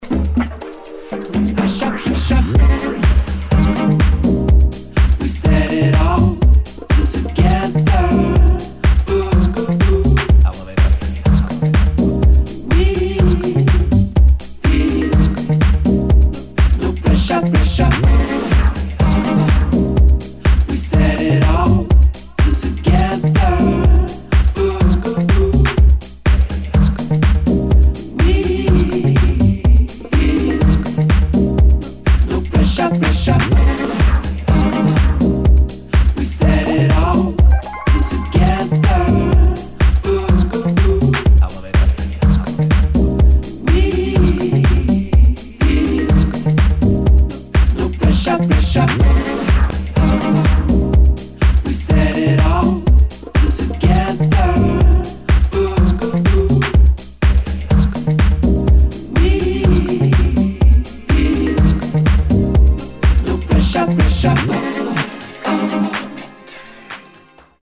[ HOUSE / TECH HOUSE ]